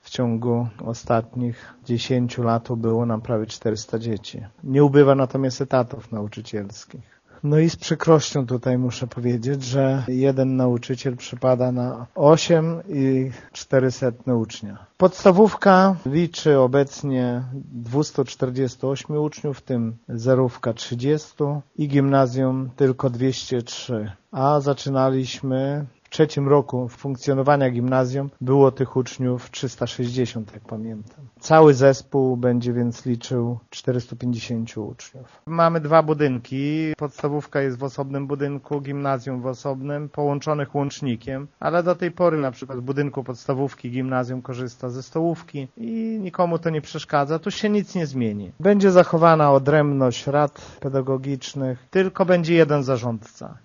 Wójt Lech Szopiński tłumaczy, że powodów wprowadzenia zmian jest kilka, ale gdyby nie niż demograficzny nie byłyby one potrzebne: